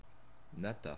Ääntäminen
France (Normandie): IPA: /na.ta/